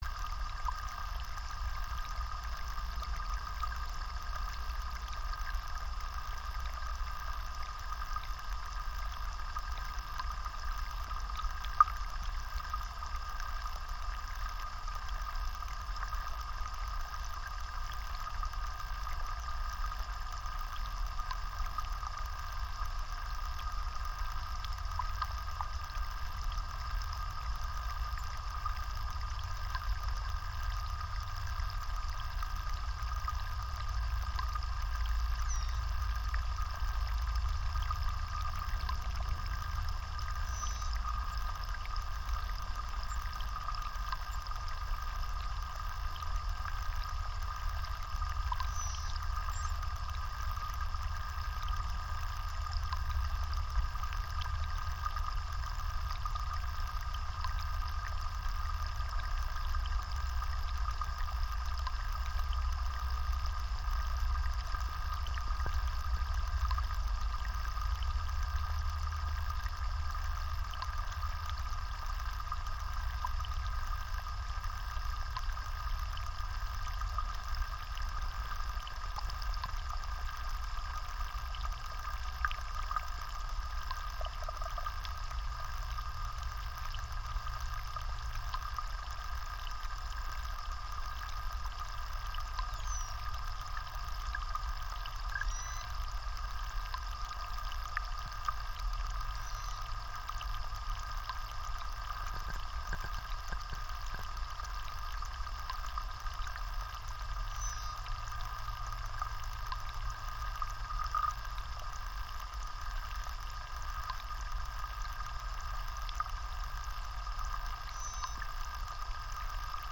Live from Soundcamp: soundcamp radio (Audio)